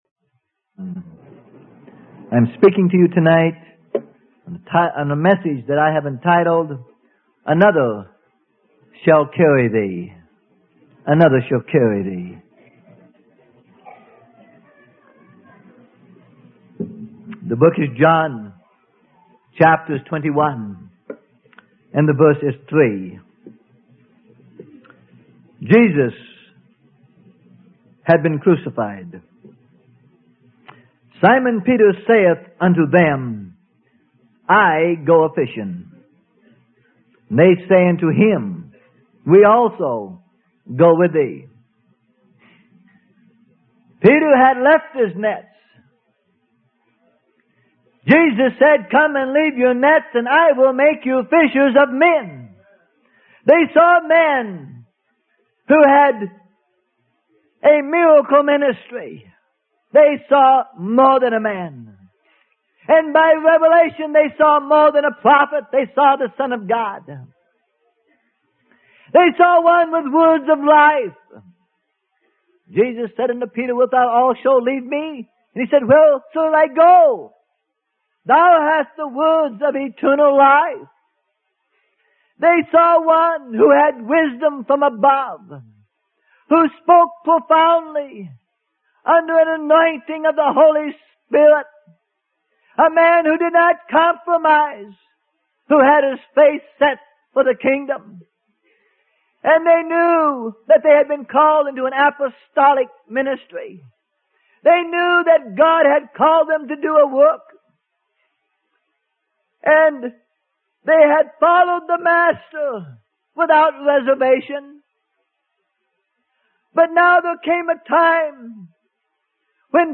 Sermon: Another Shall Carry Thee - Freely Given Online Library